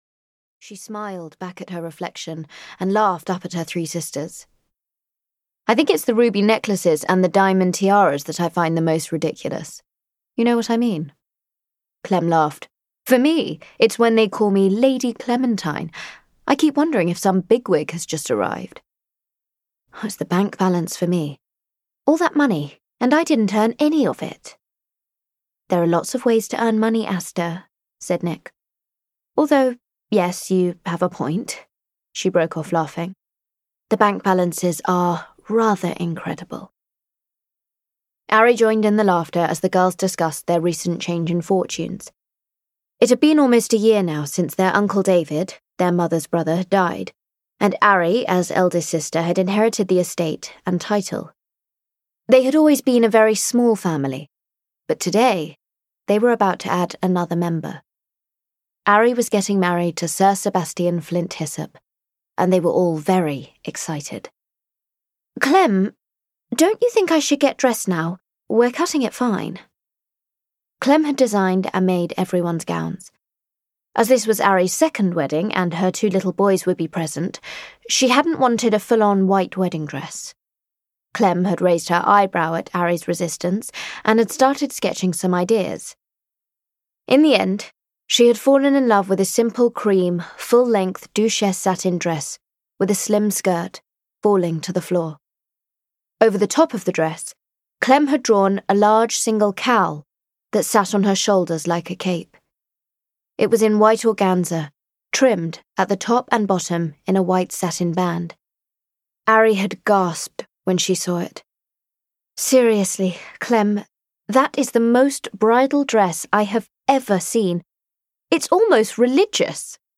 Cornish Dreams at Cockleshell Cottage (EN) audiokniha
Audiobook Cornish Dreams at Cockleshell Cottage, written by Liz Hurley.
Ukázka z knihy